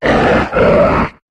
Cri de Camérupt dans Pokémon HOME.